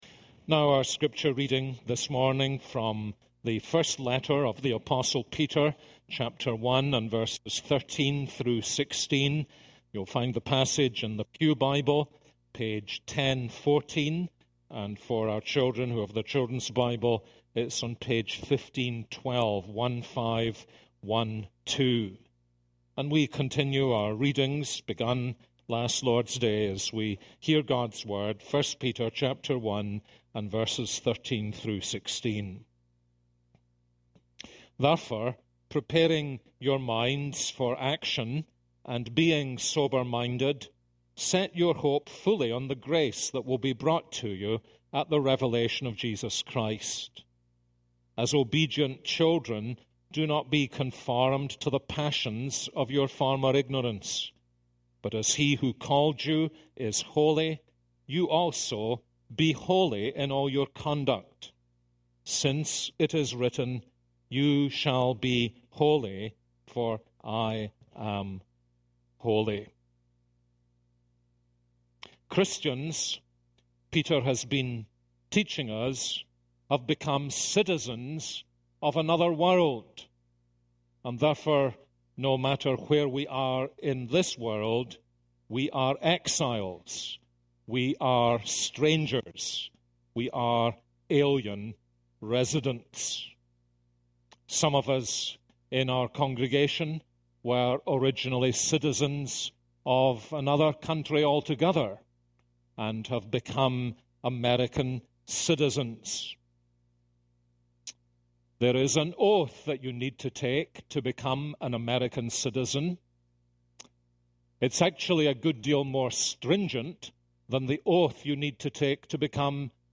This is a sermon on 1 Peter 1:13-16.